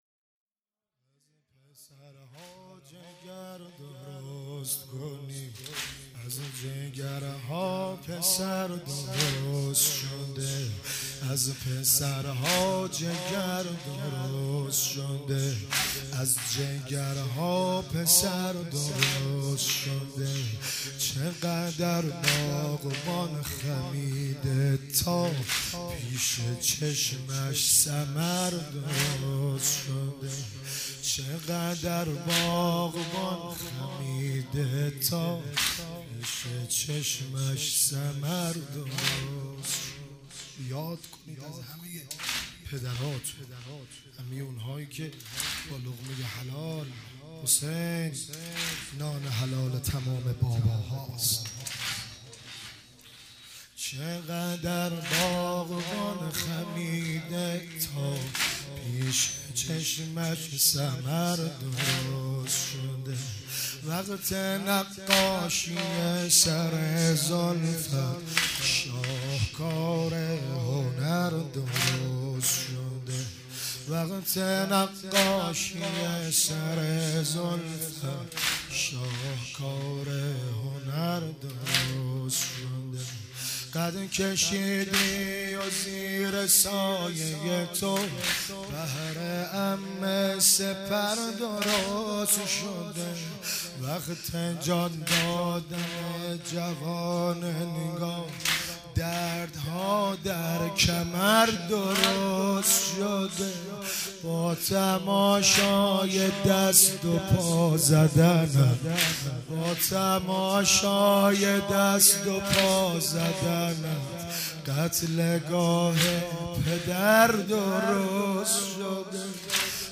شب هشتم محرم95